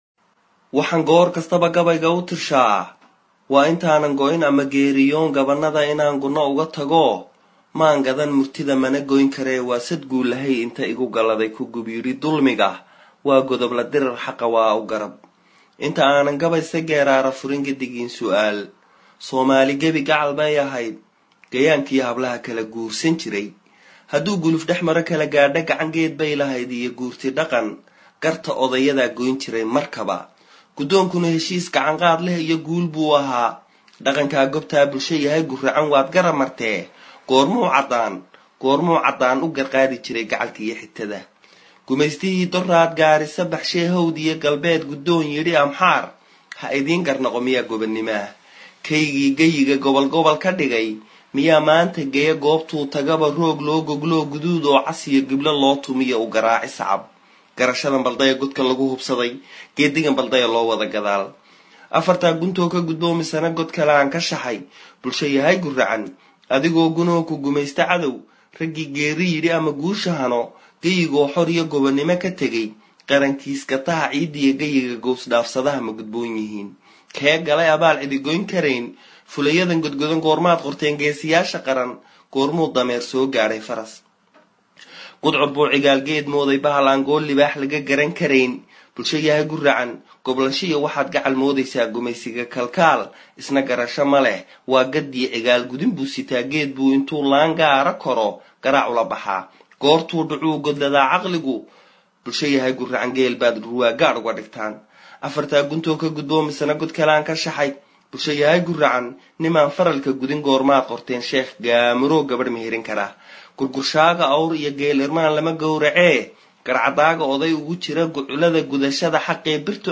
Gabygan oo nagu soo gaadhay dhinaca aalada FB ga ayaa waxaa tirinaya shaqsi ka doorbiday in labarto magaciisa in gabayga Soomaali wax uGu sheego si ay u dhageestaan Gabayga oo ay uGu mashquulin cidauu yahay qofka gabayga tirinaya.